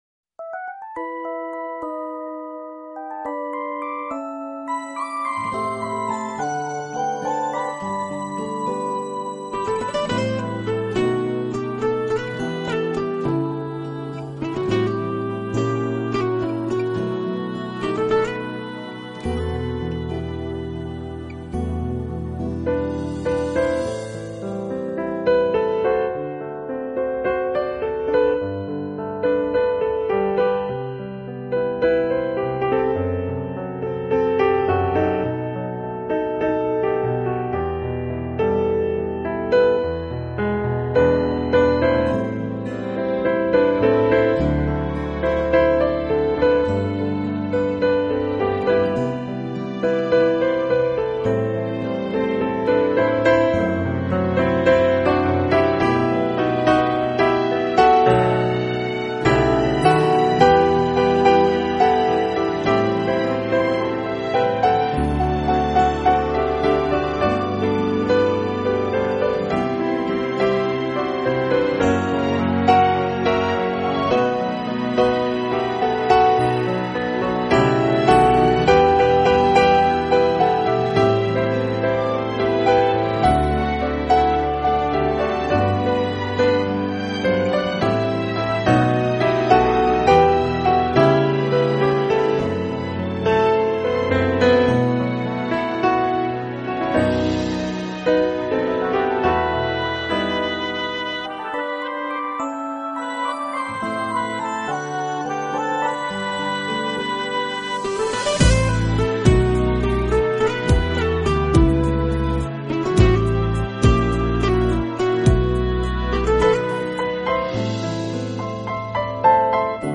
音乐风格：钢琴
无法抗拒的柔美钢琴旋律